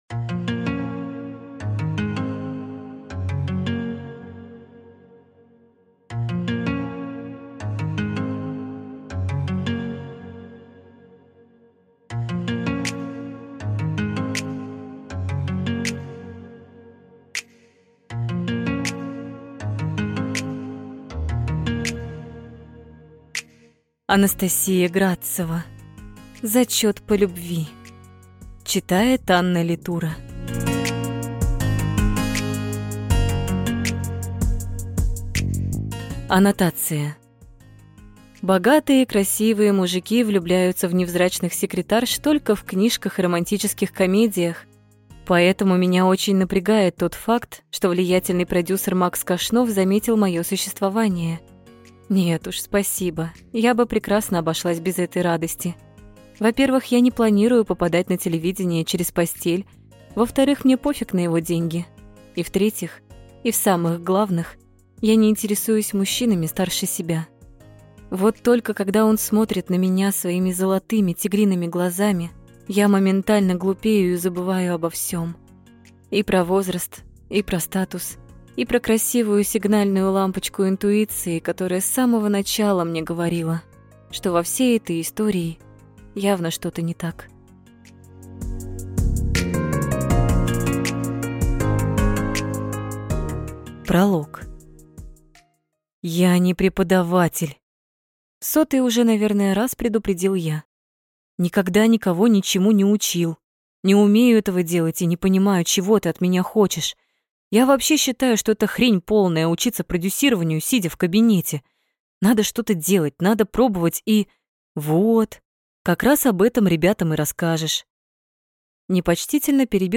Аудиокнига Зачет по любви | Библиотека аудиокниг